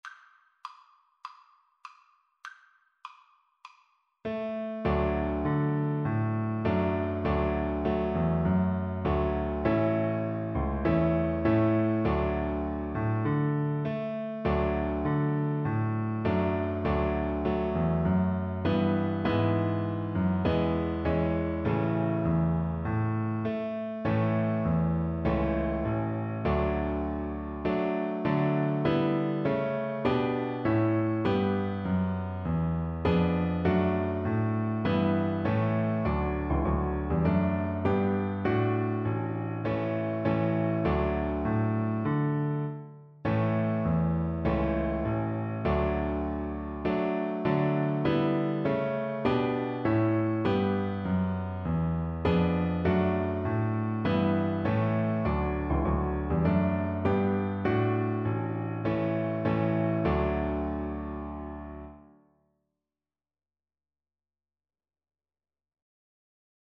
Cello
4/4 (View more 4/4 Music)
D major (Sounding Pitch) (View more D major Music for Cello )
Moderato